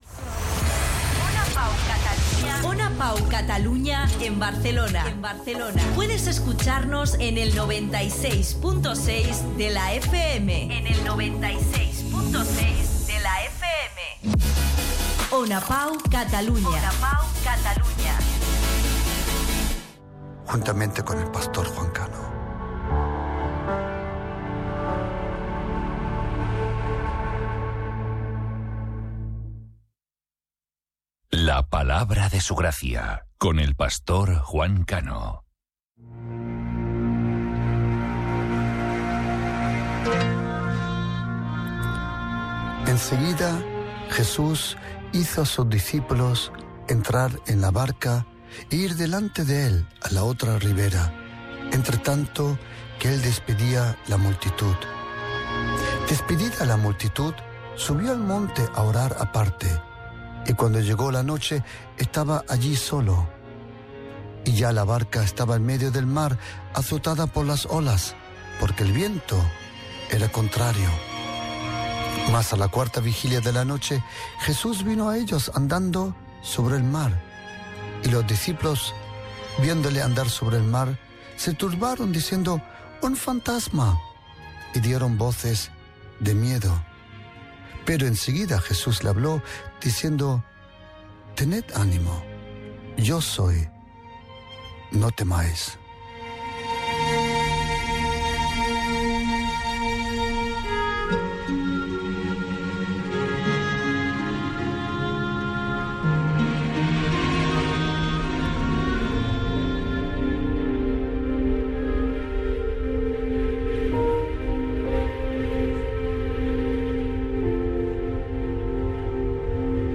Indicatiu de la ràdio, careta del programa, lectura d'un frgament de l'Evangeli i comentari
FM